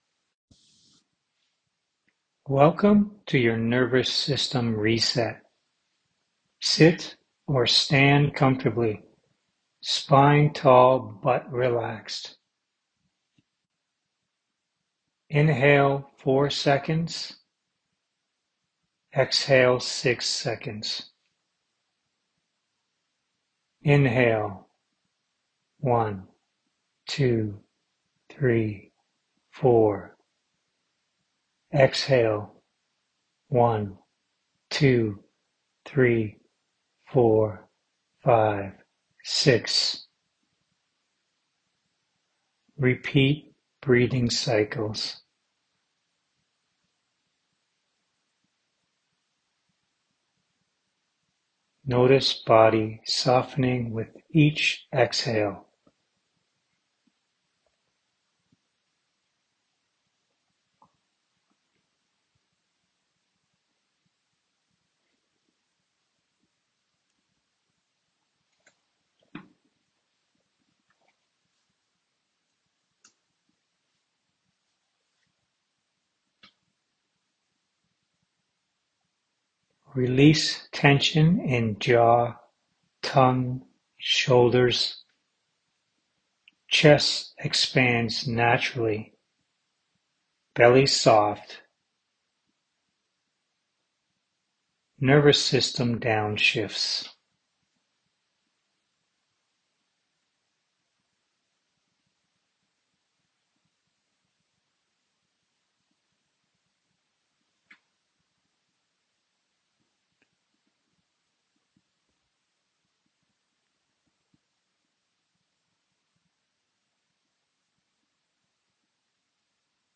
A short guided sequence to reduce hidden tension, settle your system, and help your breathing feel easier.